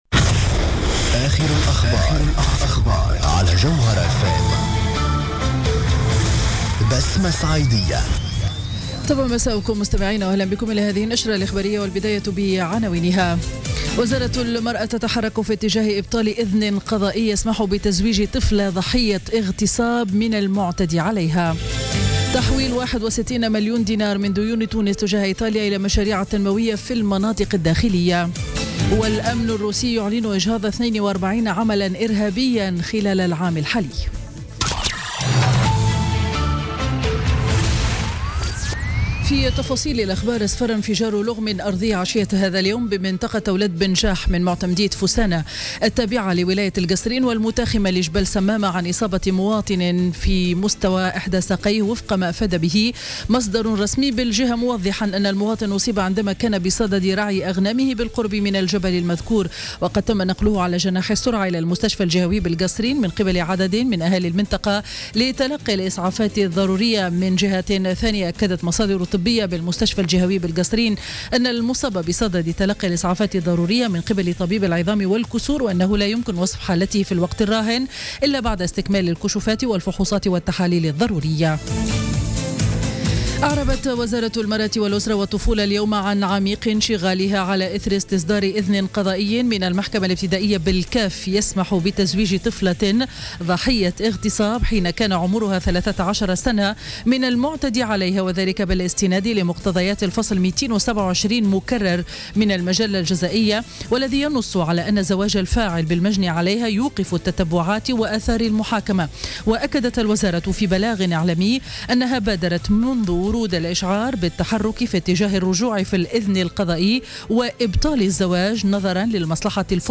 نشرة أخبار السابعة مساء ليوم الثلاثاء 13 ديسمبر 2016